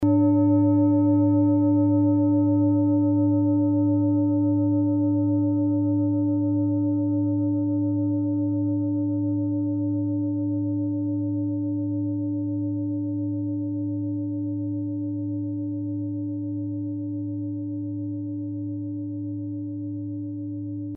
Klangschale Nepal Nr.48
(Ermittelt mit dem Filzklöppel oder Gummikernschlegel)
Der Marston liegt bei 144,72 Hz, das ist nahe beim "D".
klangschale-nepal-48.mp3